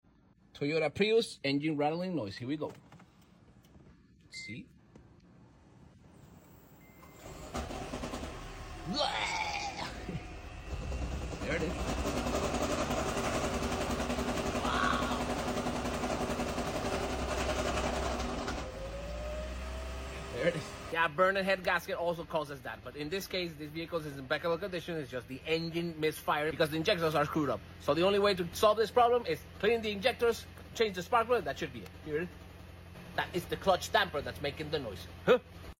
Toyota Hybrid With Engine Starting Sound Effects Free Download
Toyota hybrid with engine starting rattle noise ⚠😱😬 Have you heard this before?